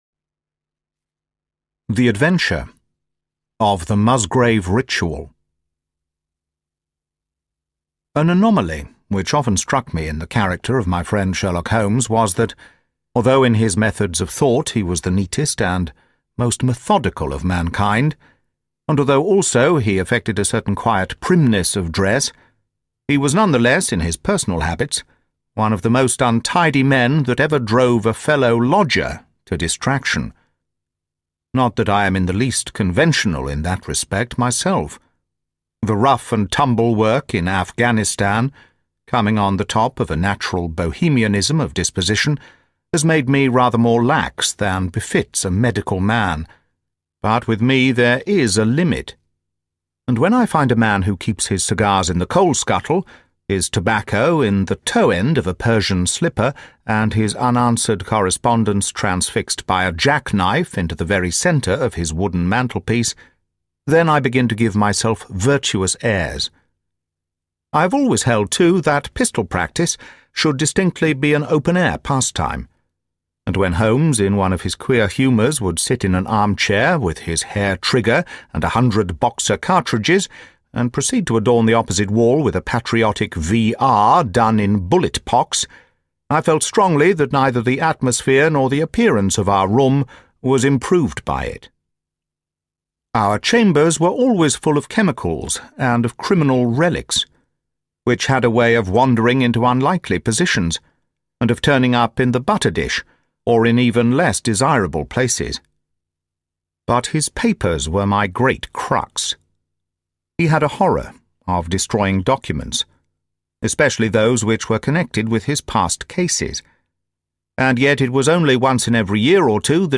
Libros Narrados